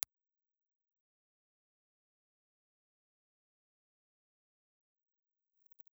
Condenser
Cardioid
Impulse Response file of the Sony ECM-56A in 'M' position
Sony_ECM56A_M_IR.wav